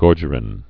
(gôrjər-ĭn)